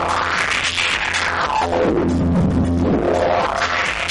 descargar sonido mp3 electronico 5